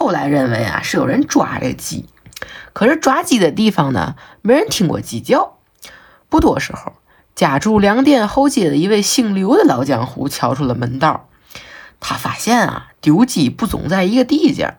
TianJin_Source.wav